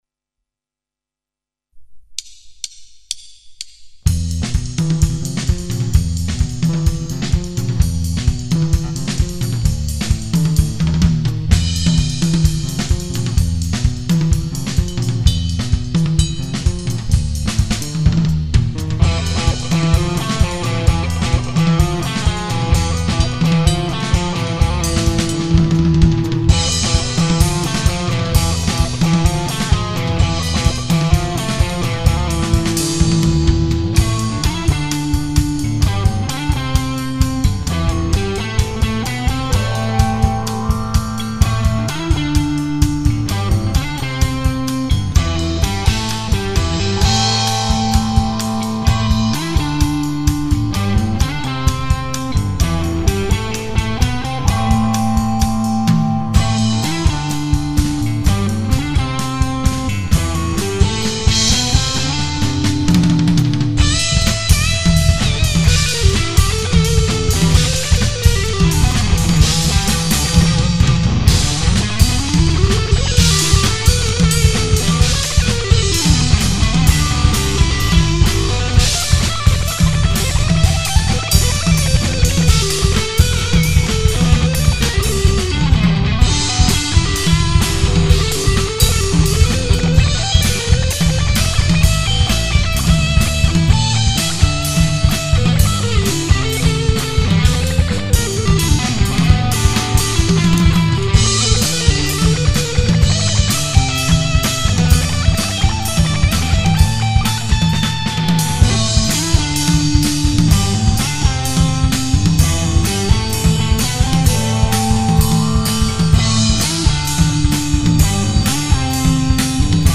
Rock & Roll, Blues, Funk, Gitara